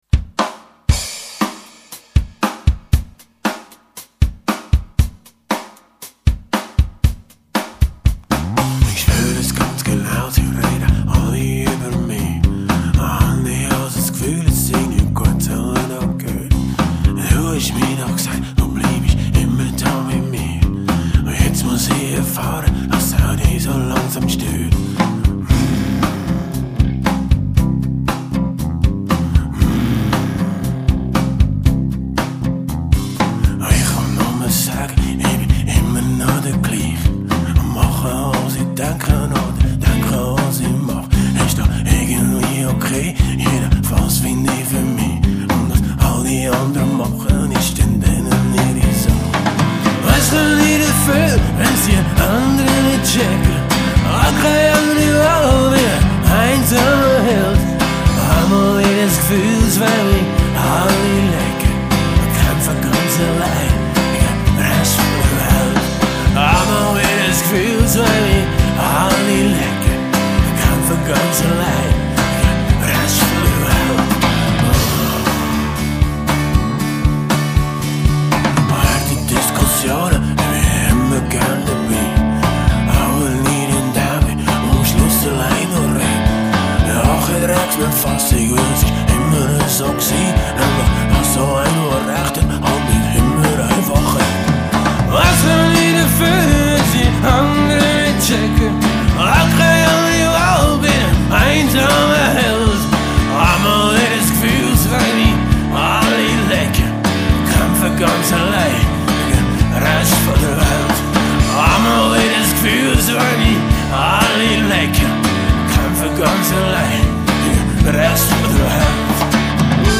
guitars
drums
bass, backing vocals
vocals